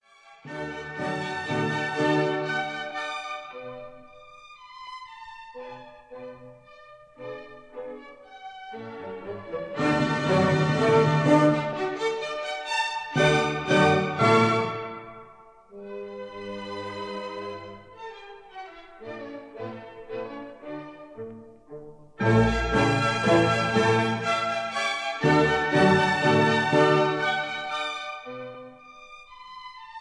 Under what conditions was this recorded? This is a 1960 stereo recording